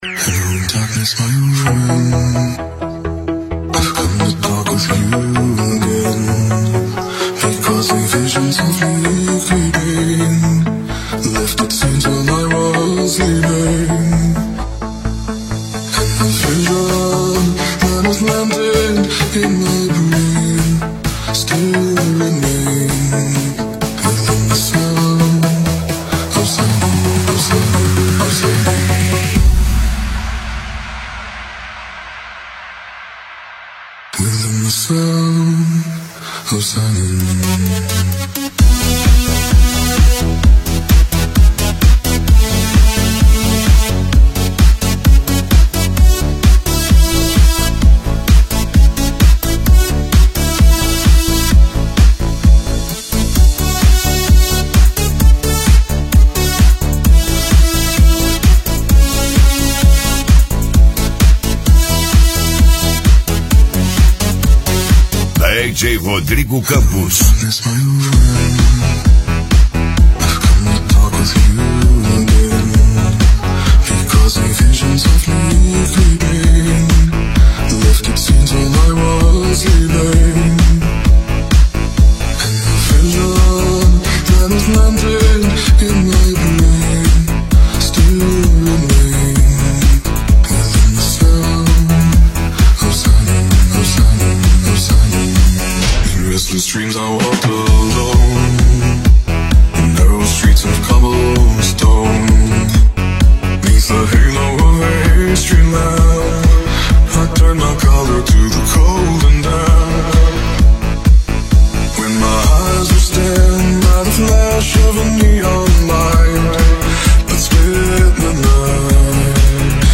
Retrô Remix